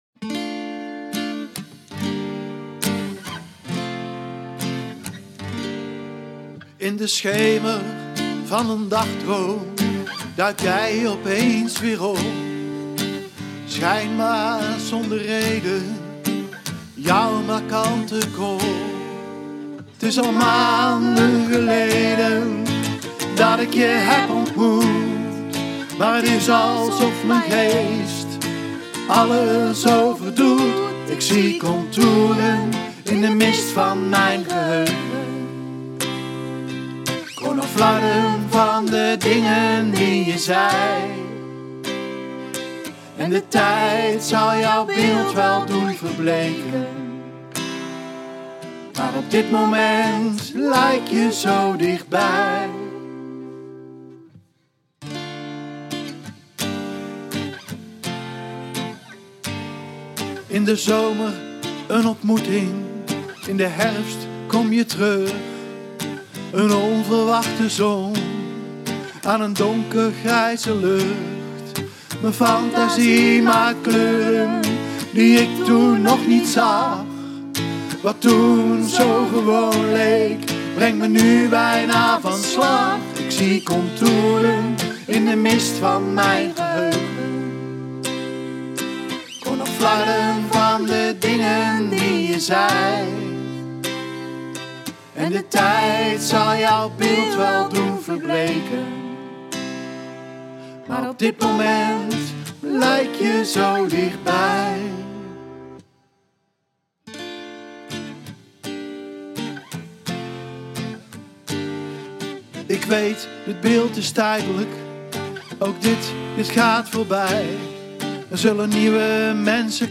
Puur, twee gitaren en twee zangstemmen.